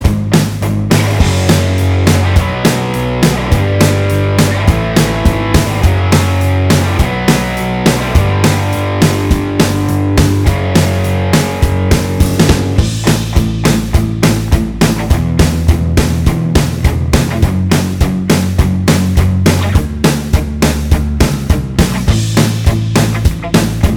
Minus Main Guitar Pop (1970s) 3:33 Buy £1.50